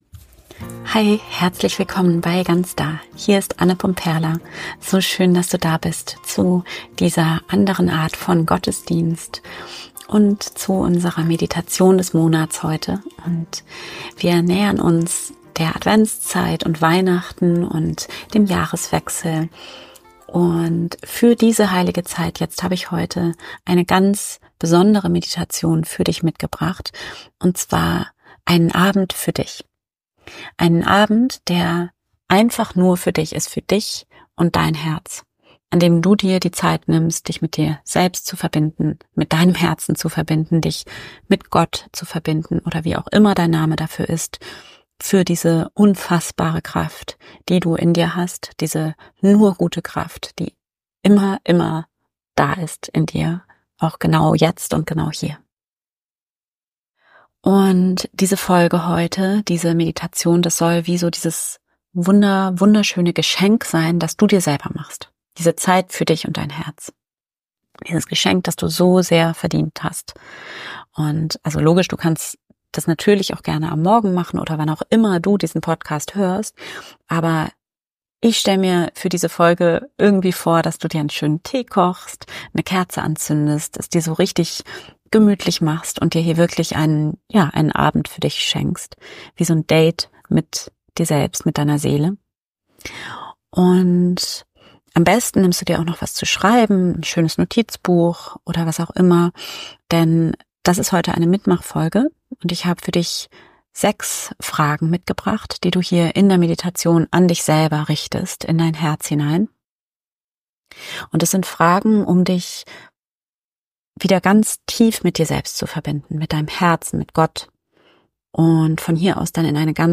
Meditation mit 6 Fragen an dein Herz